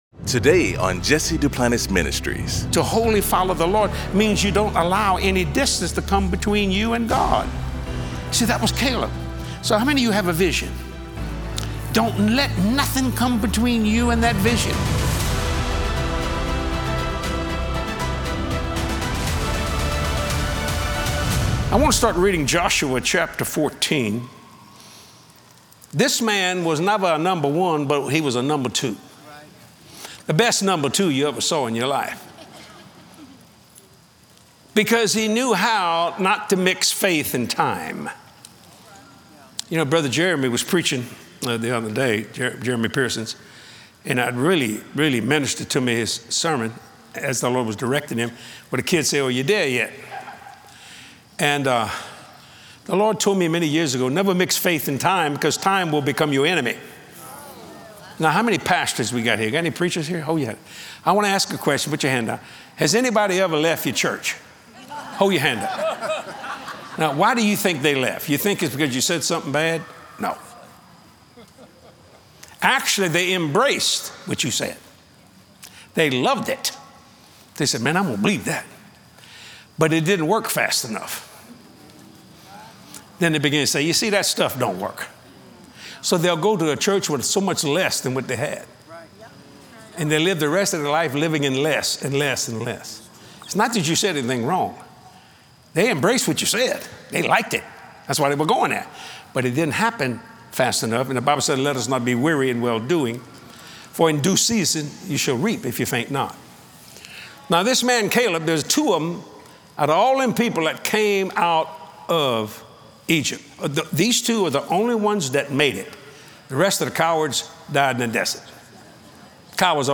In this inspirational message